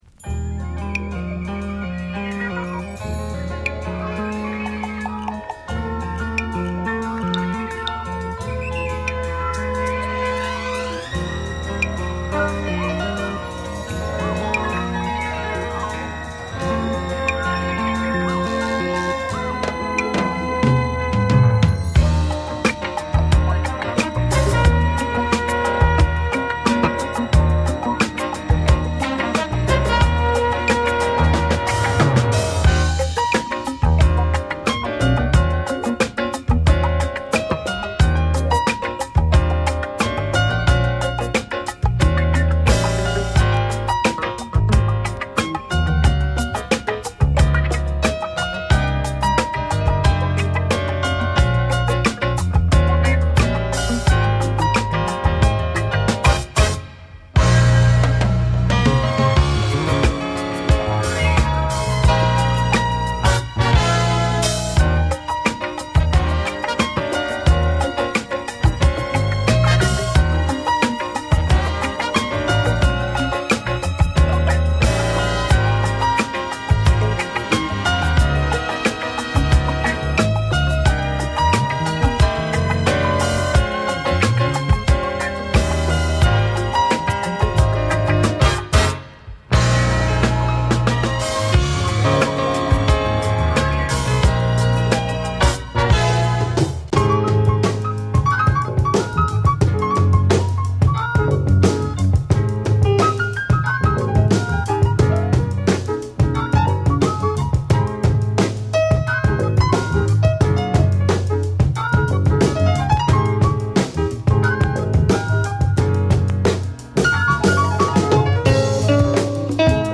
※試聴はダイジェストです。
Jazz Funk , Mellow Groove , Mix CD